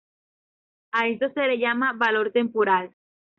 Read more Adj Noun Frequency B2 Hyphenated as tem‧po‧ral Pronounced as (IPA) /tempoˈɾal/ Etymology Borrowed from Latin temporālis In summary Borrowed from Latin temporālis.